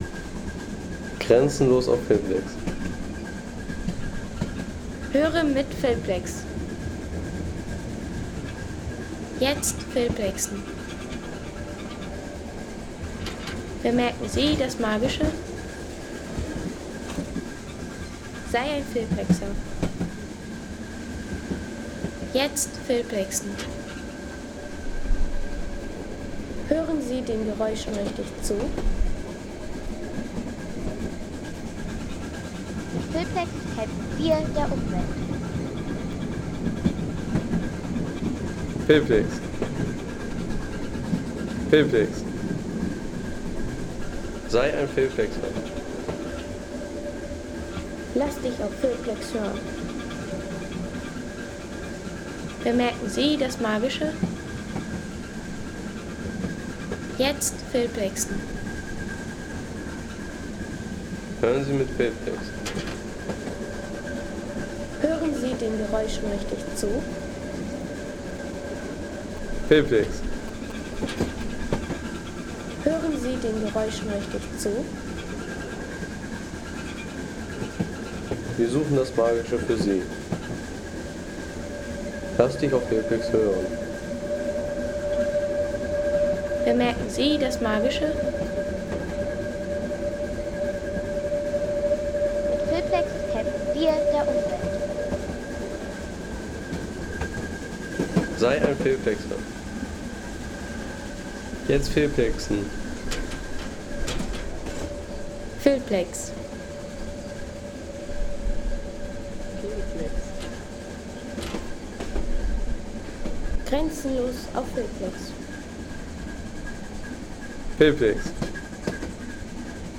Fahrt mit Dampflokomotive
Mit der Dampflokomotive durch den Harz.